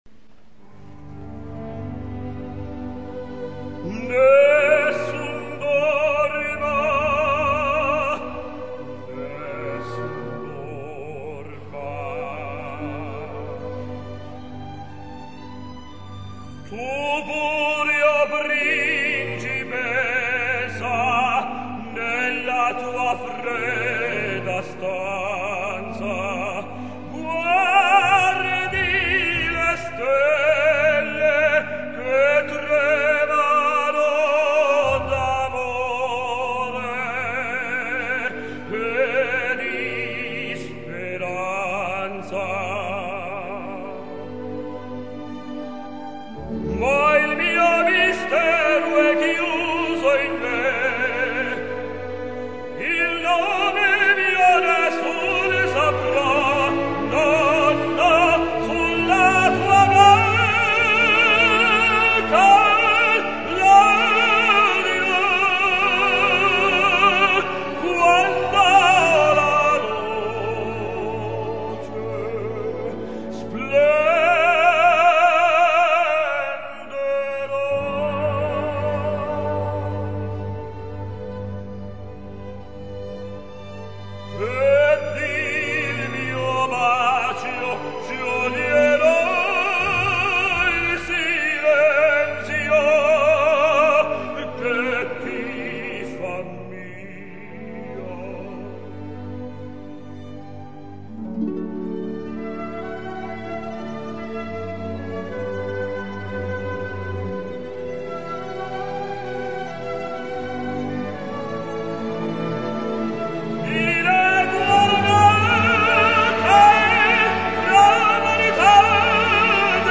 Tenors singing Nessun dorma